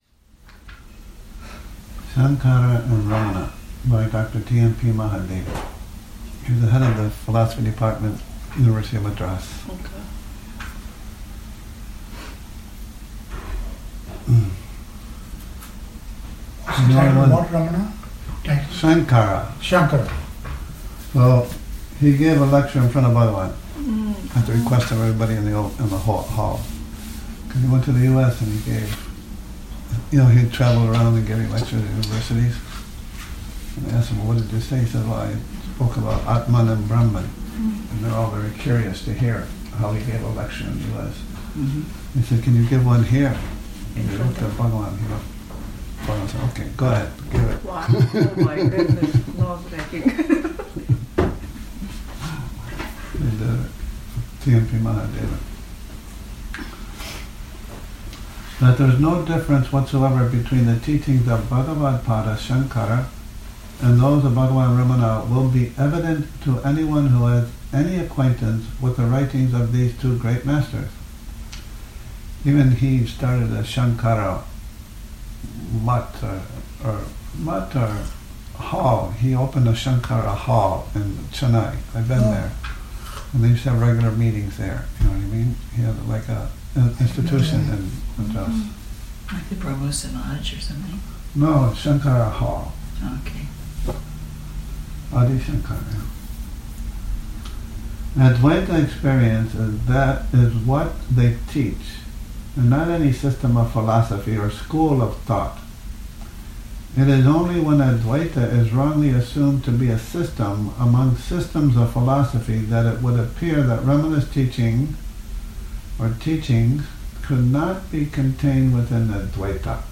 Morning Reading, 05 Nov 2019